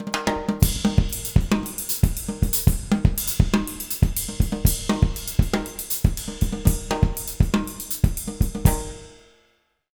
120SALSA03-R.wav